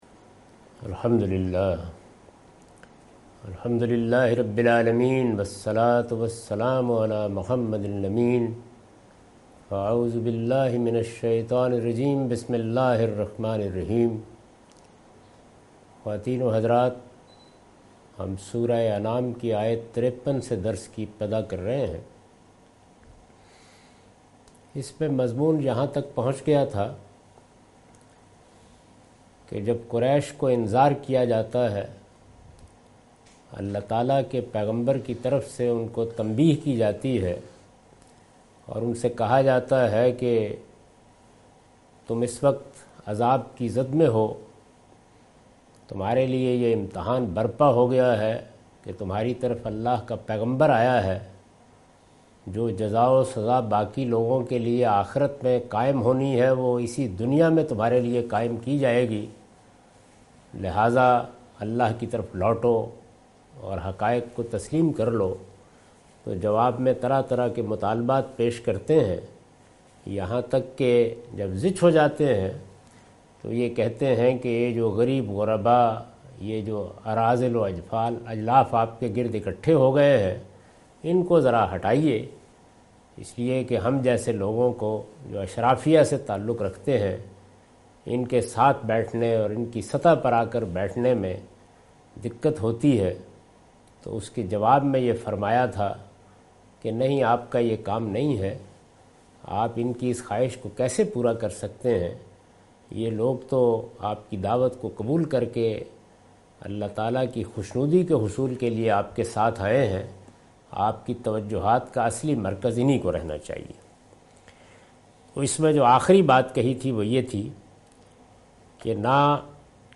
Surah Al-Anam - A lecture of Tafseer-ul-Quran – Al-Bayan by Javed Ahmad Ghamidi. Commentary and explanation of verse 53-56.